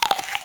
sharkBite.wav